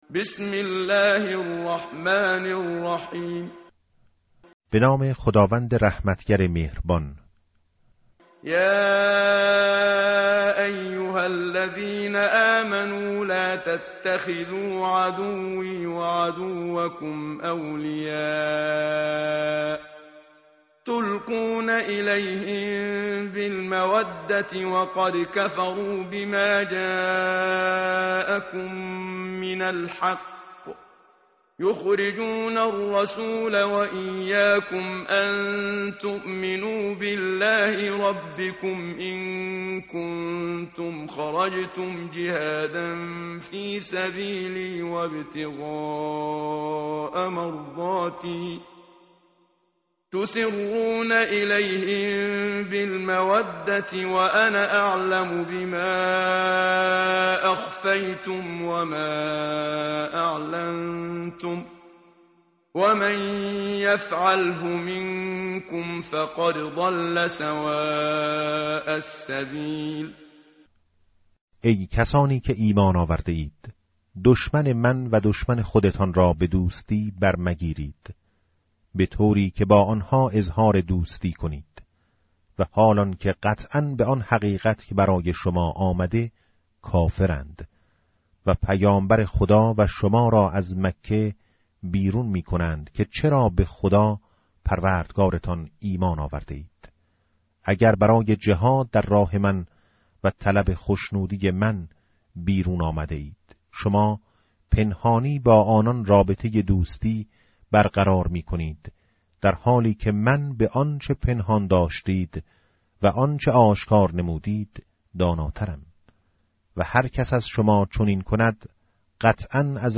متن قرآن همراه باتلاوت قرآن و ترجمه
tartil_menshavi va tarjome_Page_549.mp3